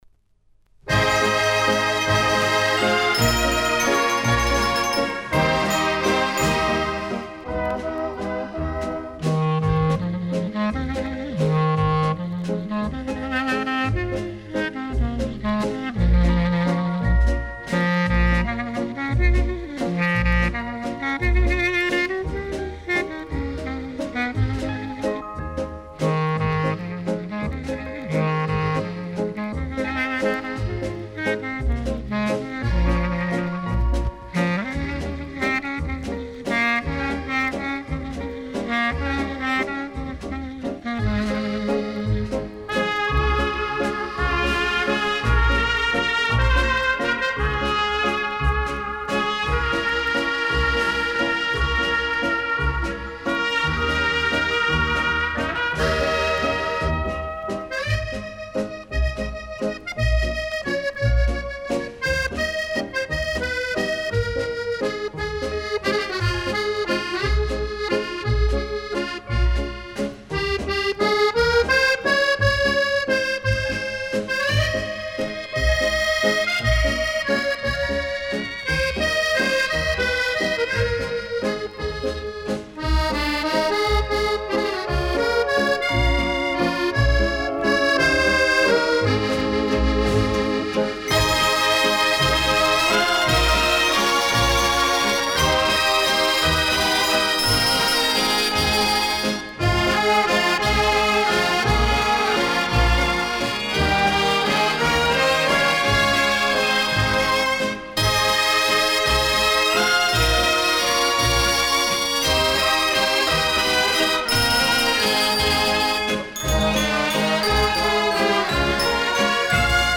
Slow Waltz